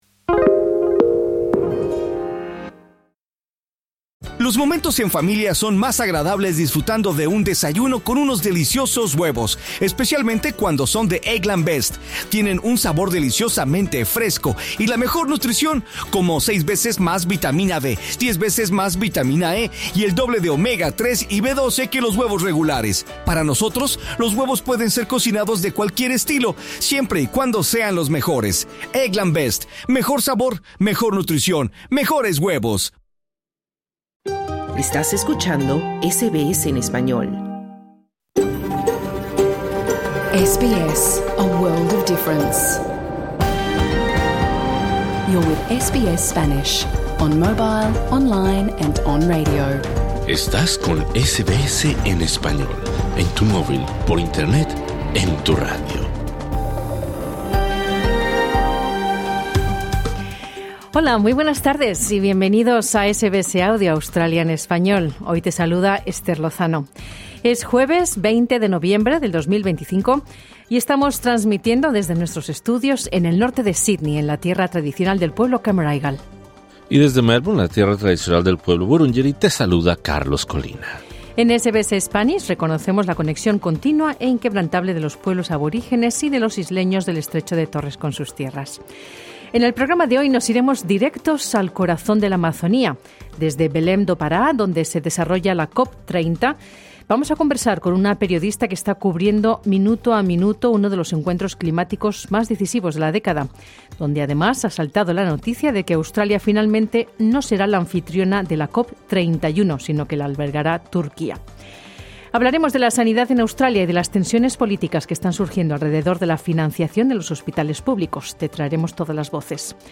Desde Belém do Pará, donde se desarrolla la COP30, conversamos con una periodista que está cubriendo minuto a minuto uno de los encuentros climáticos más decisivos de la década, donde ha saltado la noticia de que Australia finalmente no será la anfitriona de la COP31, si no que la albergará Turquia.